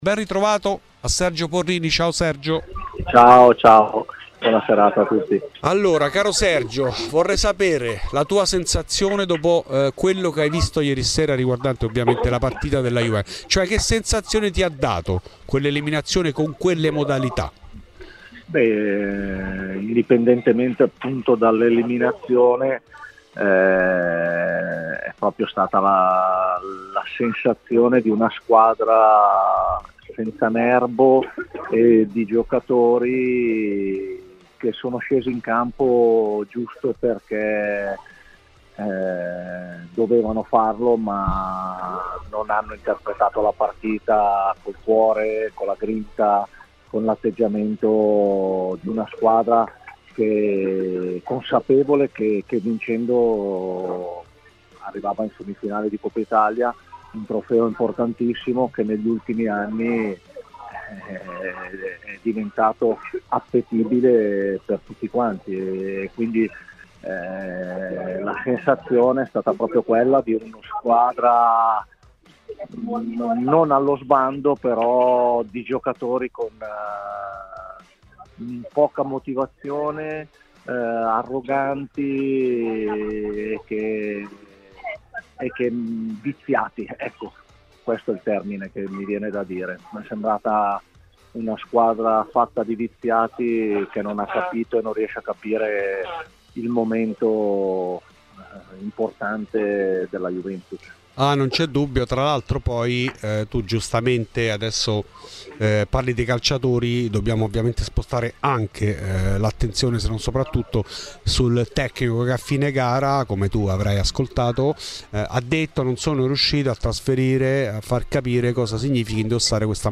In ESCLUSIVA a Fuori di Juve Sergio Porrini.
Nel podcast l'intervento integrale